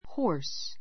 hɔ́ː r s ホ ー ス